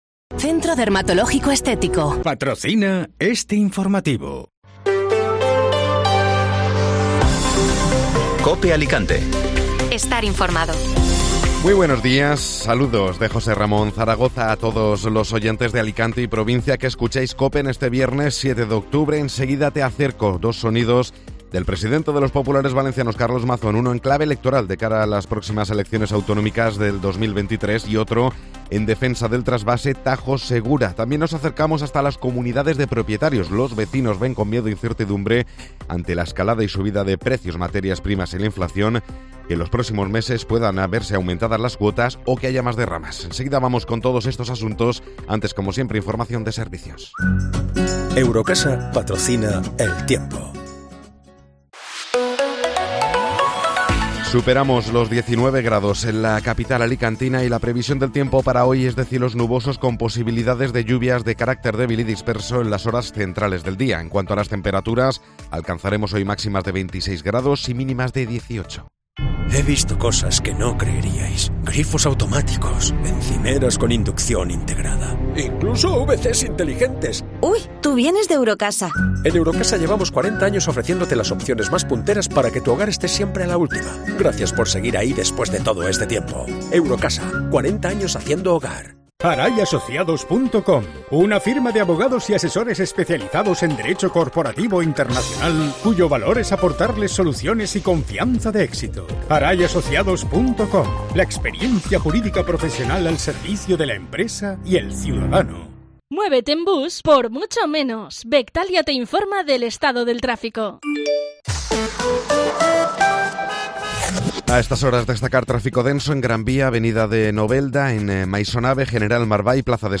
Informativo Matinal COPE (7 de octubre 2022)